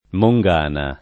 mongana [ mo jg# na ]